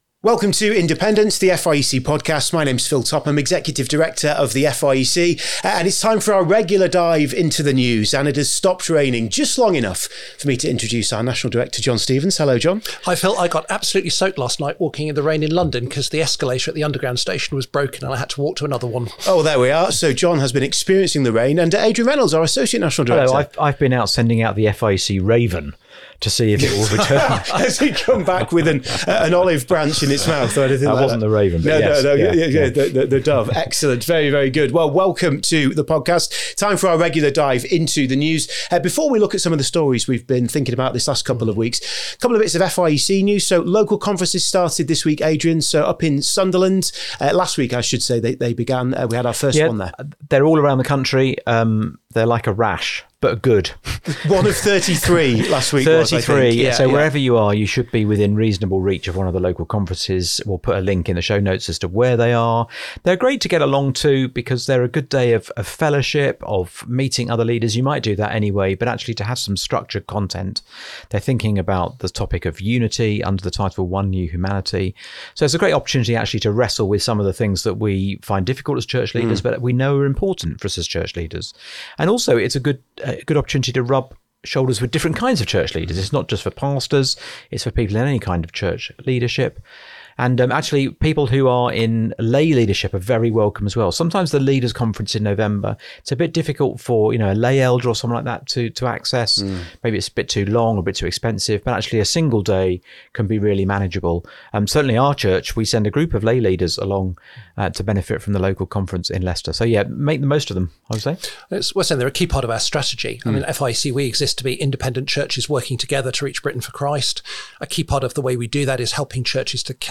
where you’ll hear conversations on helpful topics from the FIEC staff team and guests